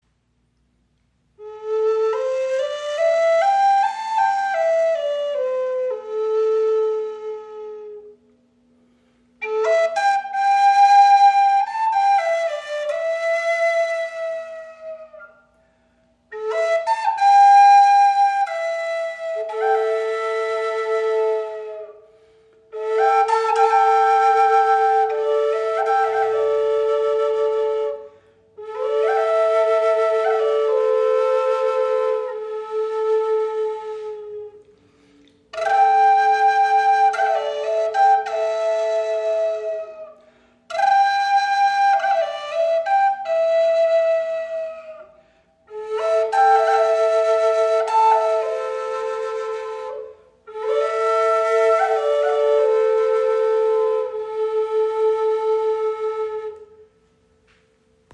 Doppelflöte aus Ästen in A im Raven-Spirit WebShop • Raven Spirit
Klangbeispiel
Diese wundervoll, aus Ästen geschnitzte Doppelflöte ist auf A Moll gestimmt und erzeugt eine magisch wirkende, warme Klangfarbe.